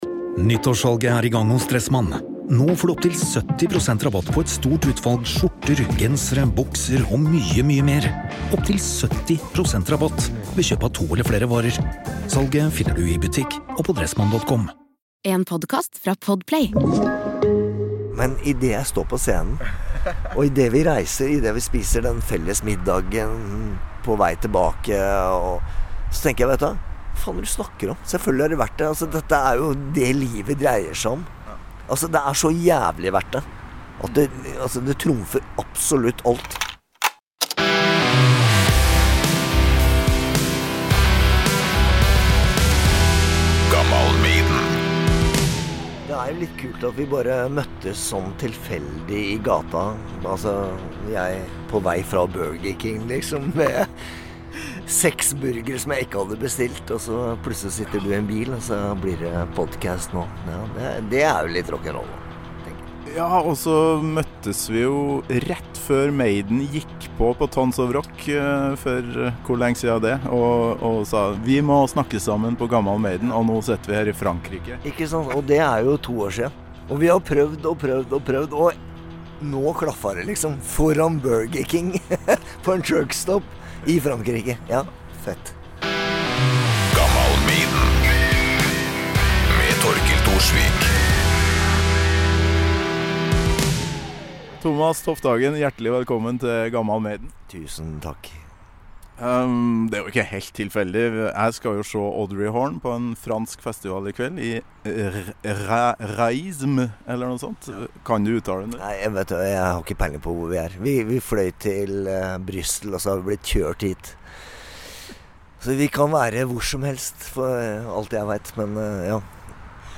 Jeg måtte bare til Frankrike på festivalen Raismesfest, så ordna det seg. Oppholdet som rockekorrespondent i Belgia bærer frukter.